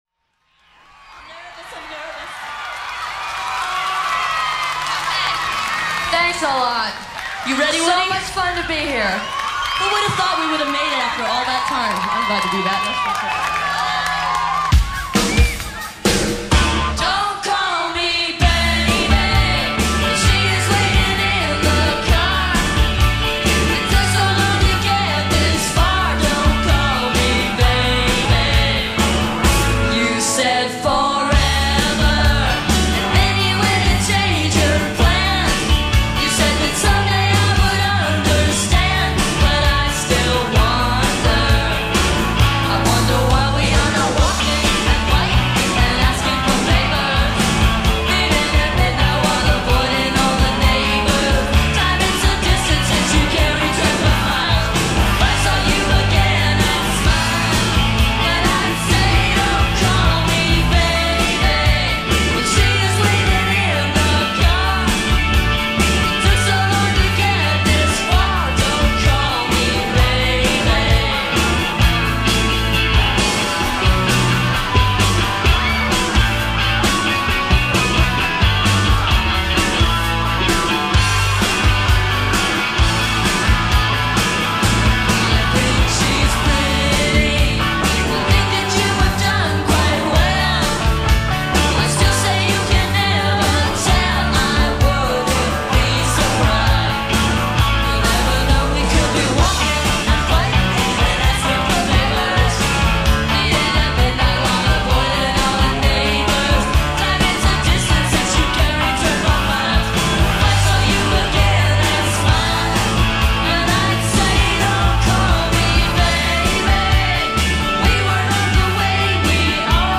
The Pop/Alt side of the 90s.